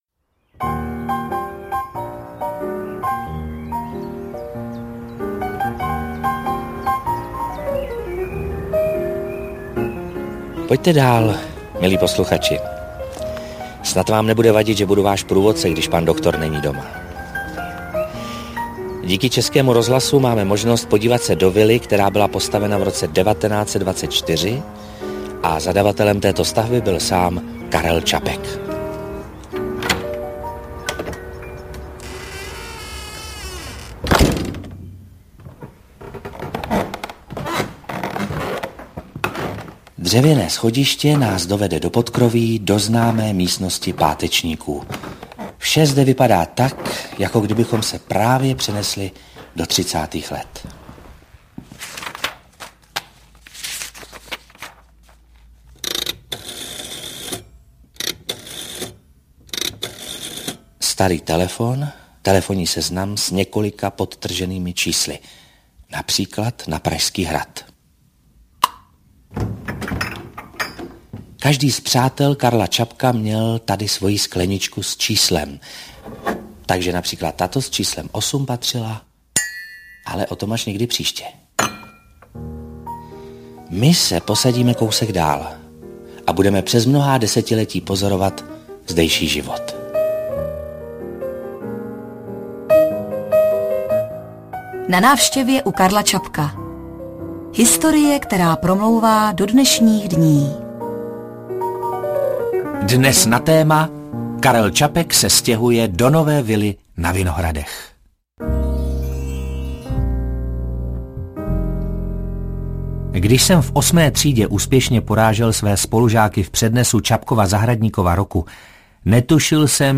Audiokniha Na návštěvě u Karla Čapka obsahuje seriál Českého rozhlasu z roku 2017 natáčený ve vile Karla Čapka na Vinohradech popisující život a dílo slavného autora na základě konkrétních předmětů nalezených ve spisovatelově vile.
Ukázka z knihy